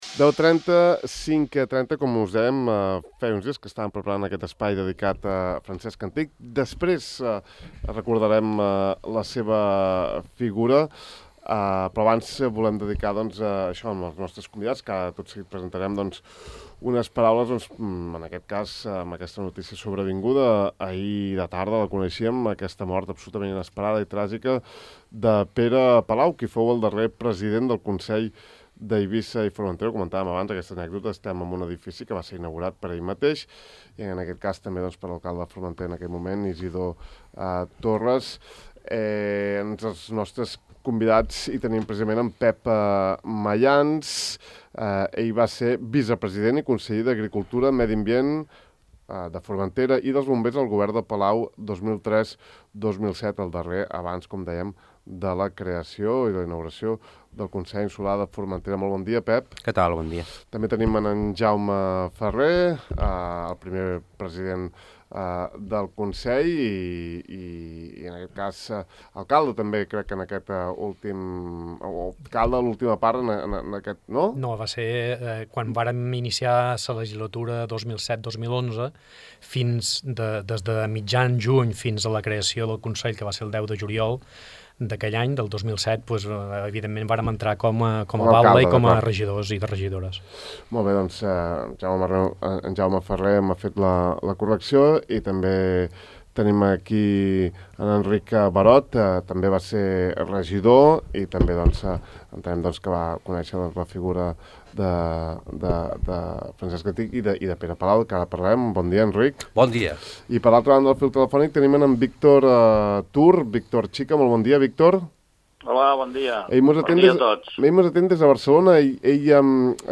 Aquesta setmana hem volgut recordar l’expresident durant vuit anys (en dues legislatures no seguides) de les Illes Balears, tristament desaparegut el 2 de gener d’enguany, en una taula rodona amb la participació de diverses persones que van prendre part en la política local en els temps del president Antich.